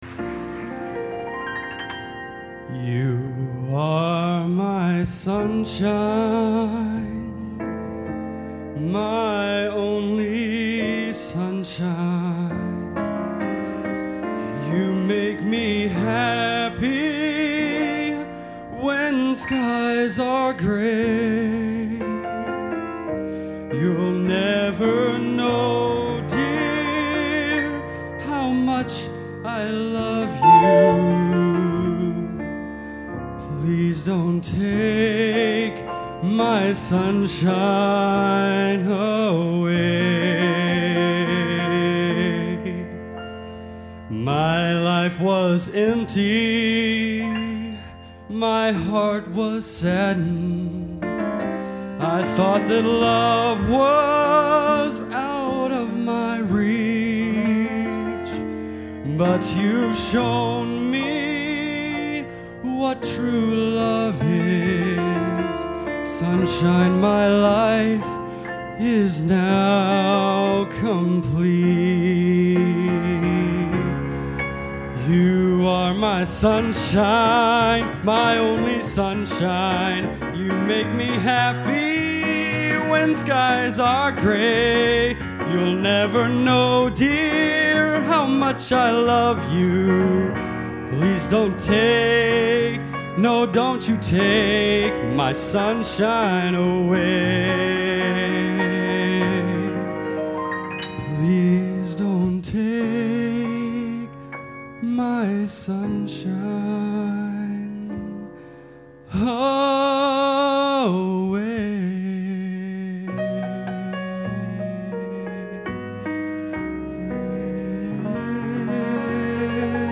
Genre: Popular / Standards | Type: